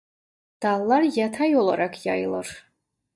Pronounced as (IPA) [jɑ.tɑj]